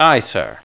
balloonMove.mp3